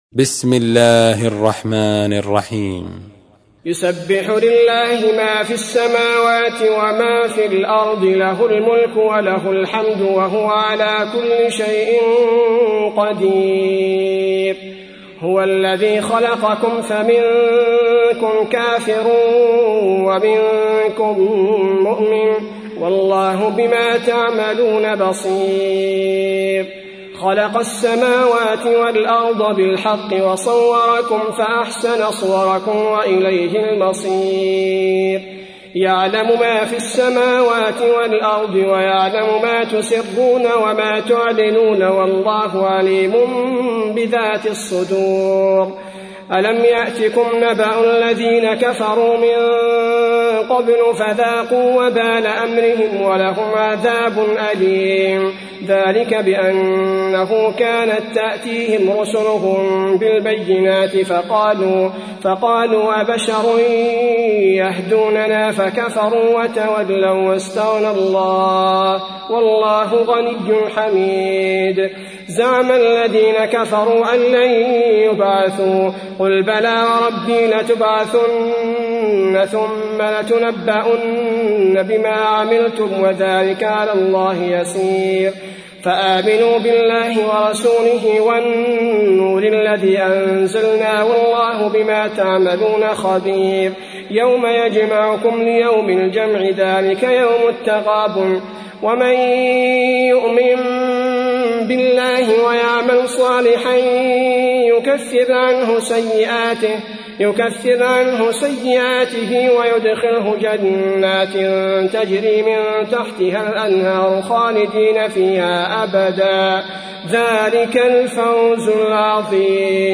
تحميل : 64. سورة التغابن / القارئ عبد البارئ الثبيتي / القرآن الكريم / موقع يا حسين